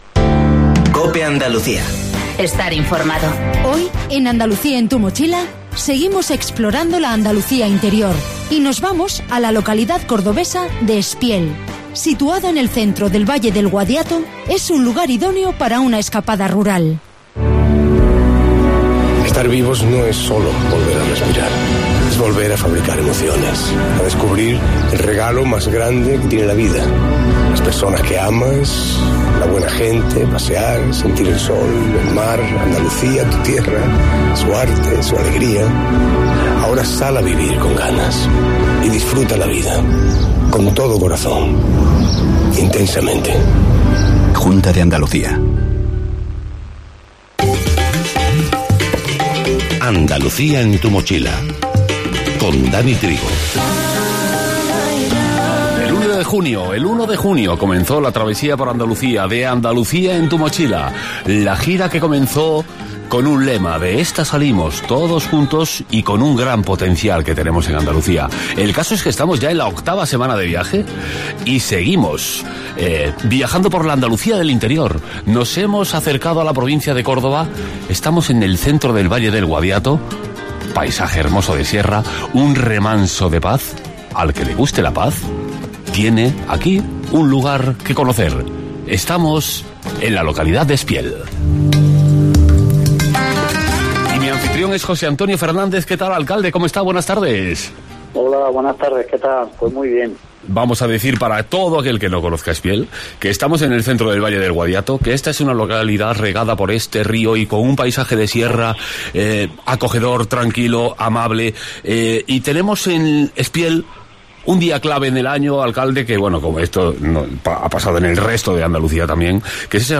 El alcalde de Espiel, José Antonio Fernández, ha sido el anfitrión de 'Andalucía en tu mochila' y ha recordado las oportunidades que nos ofrece este pueblo de interior andaluz